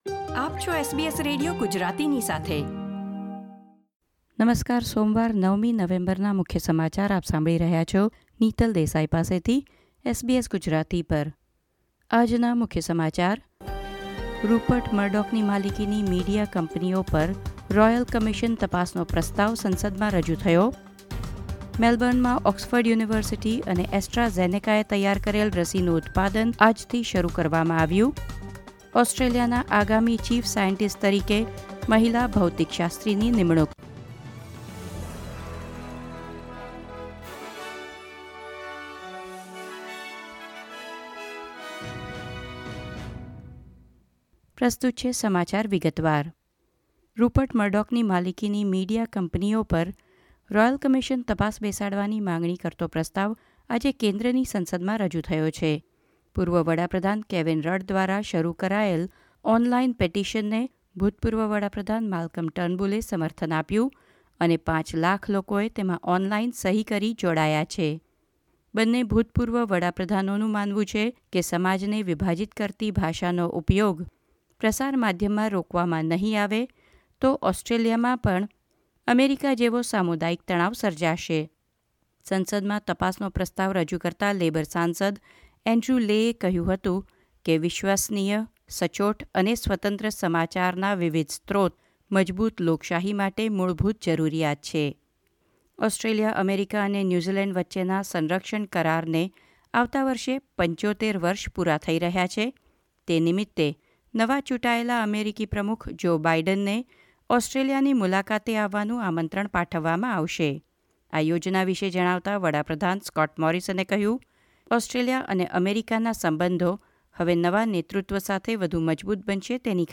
SBS Gujarati News Bulletin 9 November 2020
gujarati_0911_newsbulletin.mp3